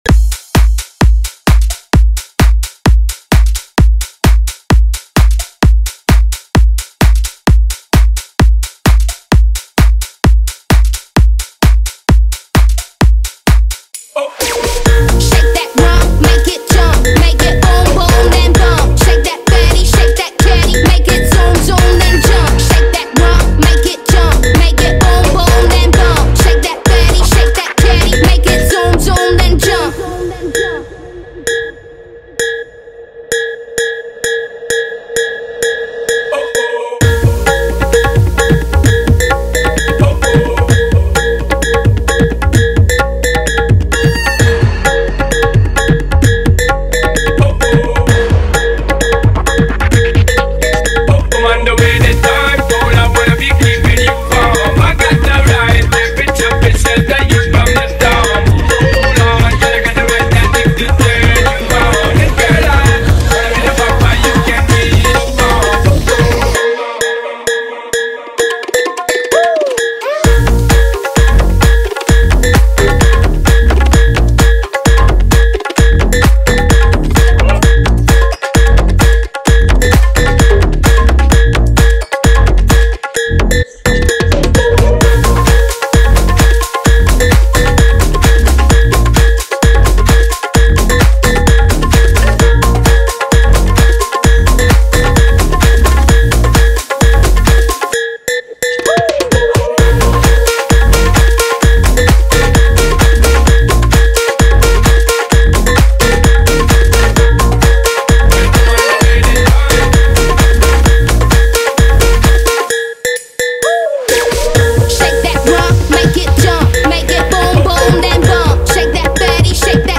• Deep house bass with strong drops
• Clean and professional mixing
• Mix Type: House Remix / EDM Fusion
• EDM + Tabla Fusion
• High Energy House Beats
• Smooth Transitions